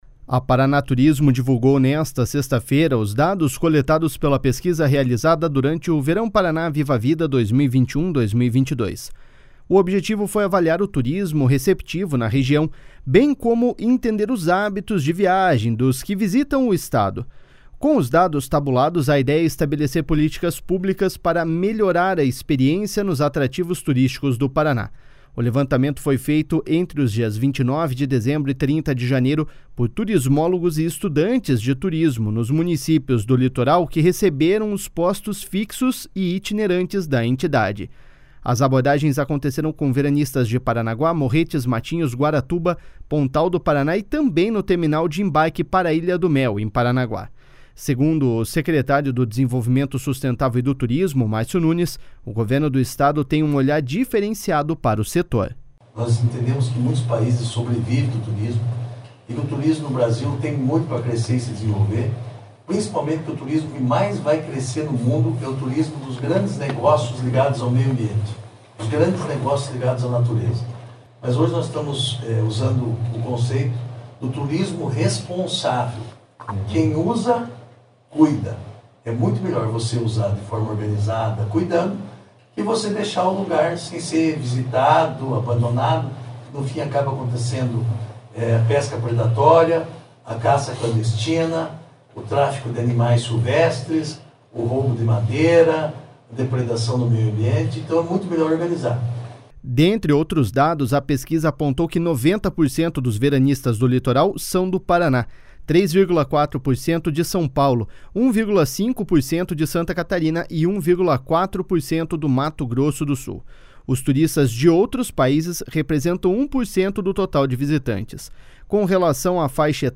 Segundo o secretário do Desenvolvimento Sustentável e do Turismo, Márcio Nunes, o Governo do Estado tem um olhar diferenciado para o setor.// SONORA MÁRCIO NUNES.//